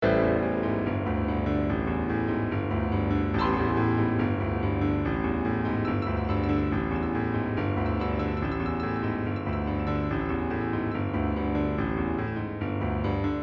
海におちる ドボーン、ハラ ハラ、ループ素材 00.13